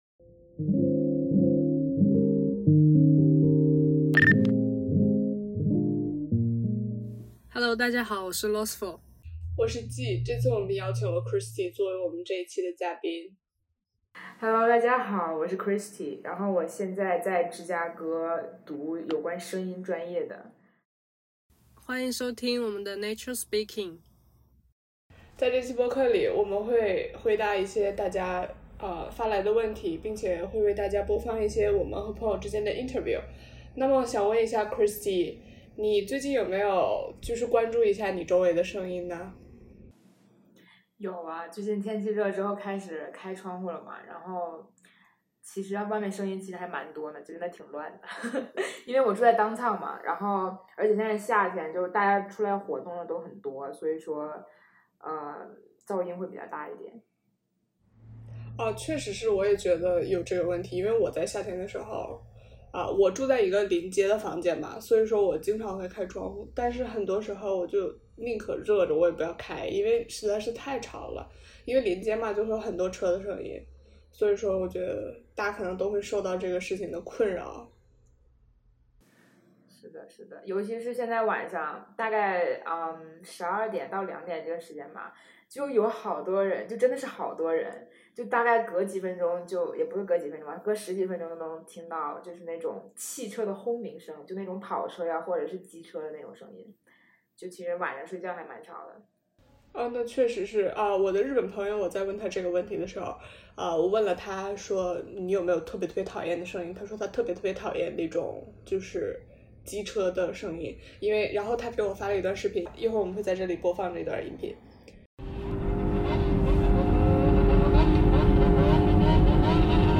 interviewed their friends and family about the changes they had noticed in nature since the beginning of the COVID-19 pandemic.